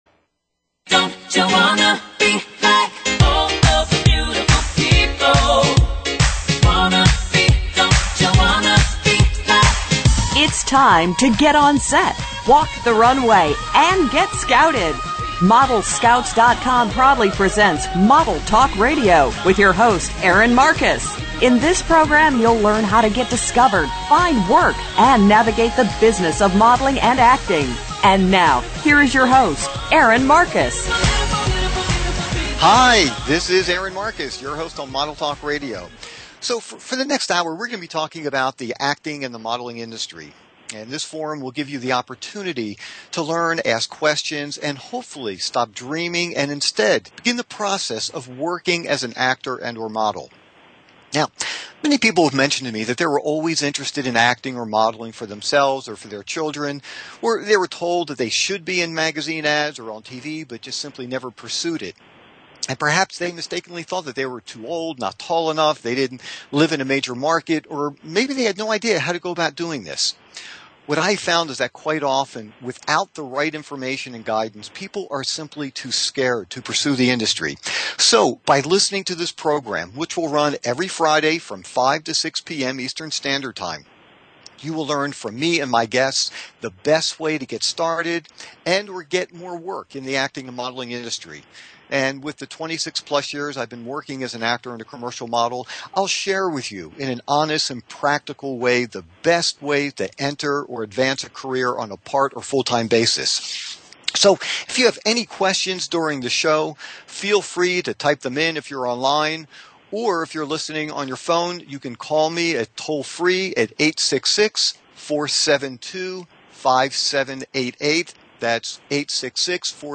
How to Become a Model (Model Talk Interview)